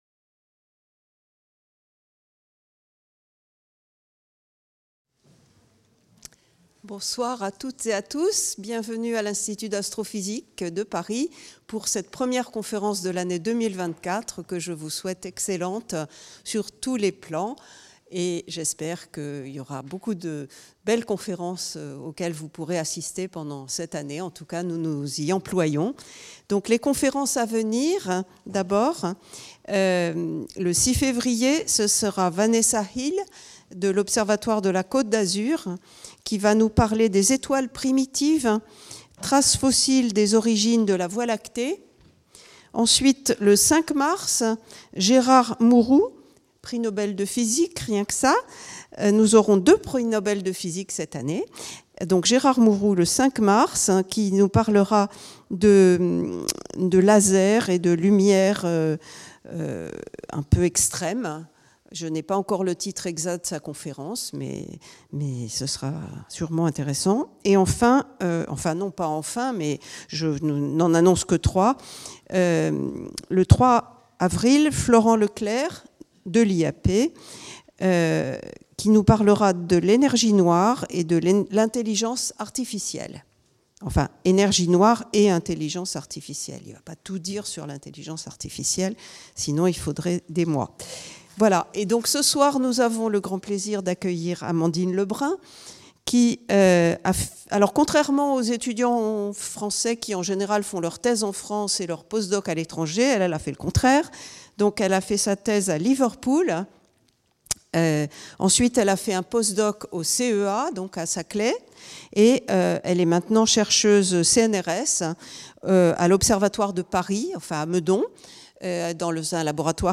Une conférence publique